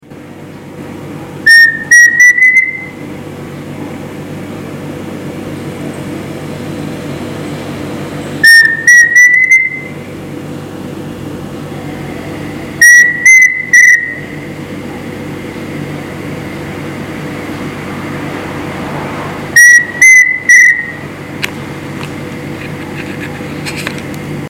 Yasiyateré Grande (Dromococcyx phasianellus)
Nombre en inglés: Pheasant Cuckoo
Fase de la vida: Adulto
Localidad o área protegida: Chapada dos Guimaraes
Condición: Silvestre
Certeza: Vocalización Grabada